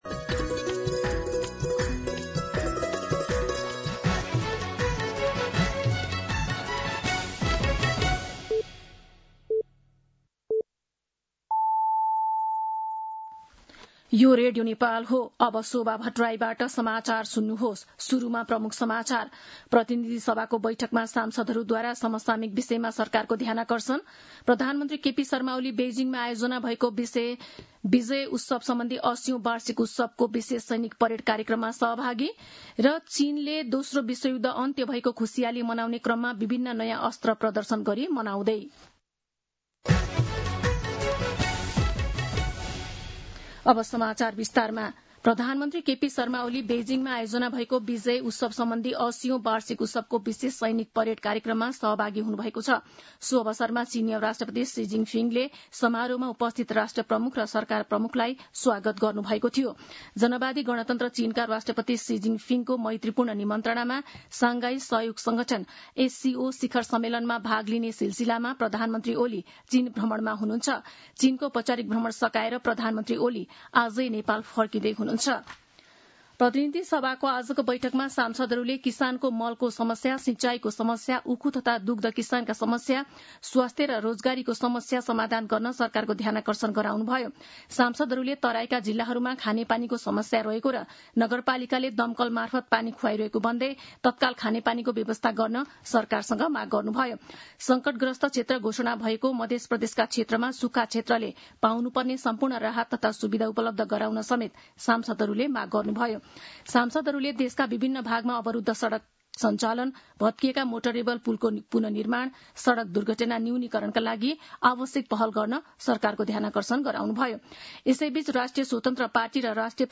दिउँसो ३ बजेको नेपाली समाचार : १८ भदौ , २०८२
3-pm-News.mp3